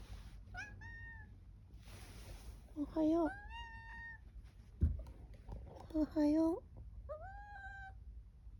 ほんとにおはようって言ってますね！すごい
TAICHO_OHAYO.mp3